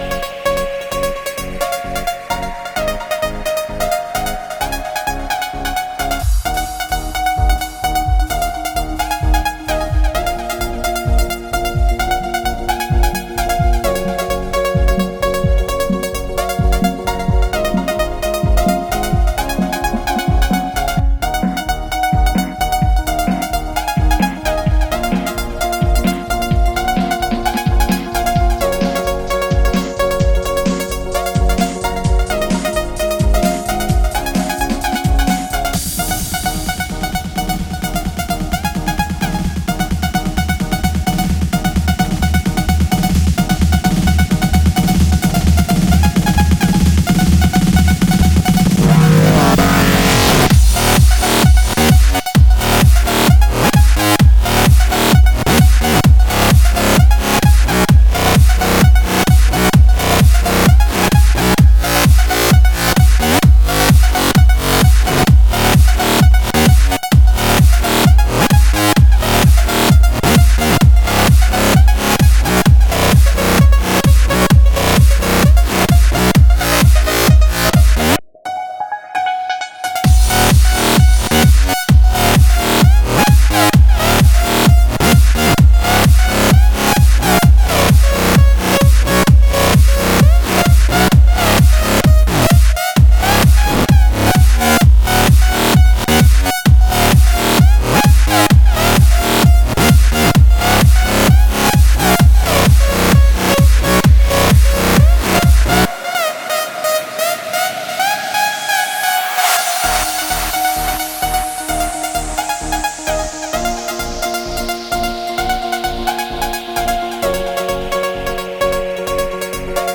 Жанр: Hard Electro